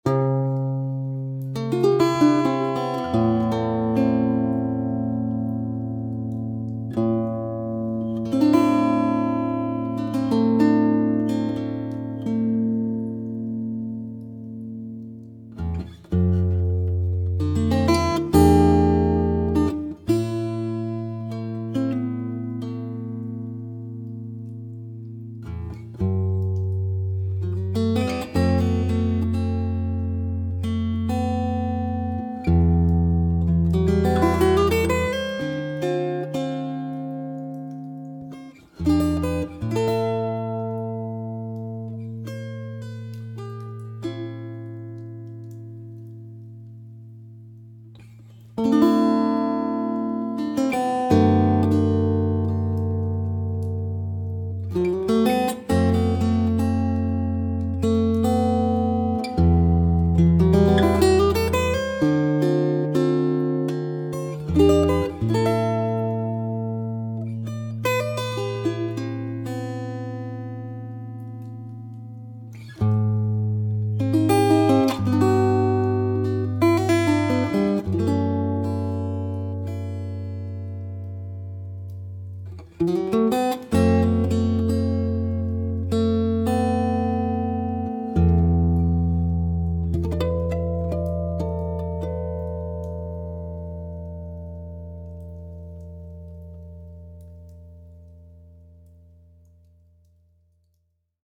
Сольное исполнение. Записано в каминном зале с микрофонов и линии. Практически не редактировалось - as is.